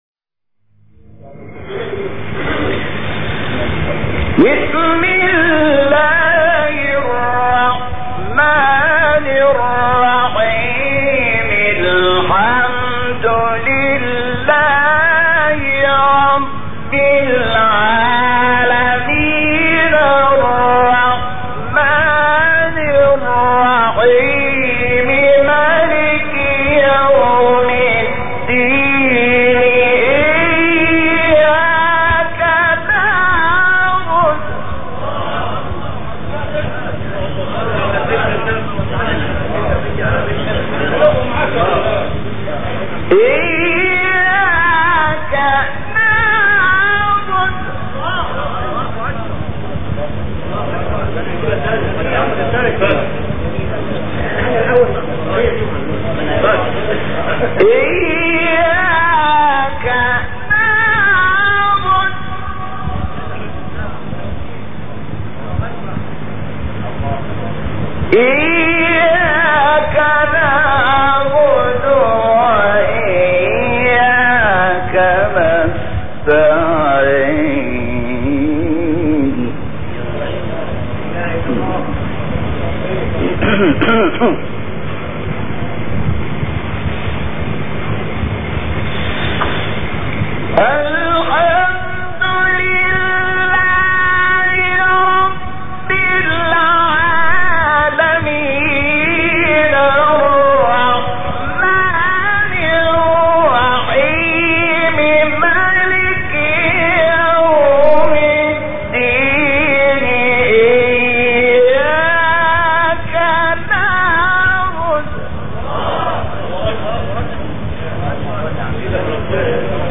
تلاوت سوره حمد
سوره حمد با صوت شحات محمد انور